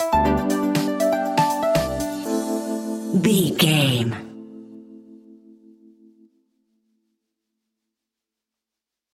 Funky Feel Good Electro Stinger.
Aeolian/Minor
groovy
uplifting
driving
energetic
electric piano
bass guitar
synthesiser
drums
funky house
upbeat